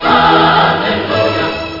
It only took over a decade, but you can FINALLY! change the default notification sound on iOS as of the latest 17.2 beta.
I kind of want to use this for a while but something tells me it would get annoying very quickly 😂 any Worms fans recognise this?